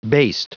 Prononciation du mot baste en anglais (fichier audio)
Prononciation du mot : baste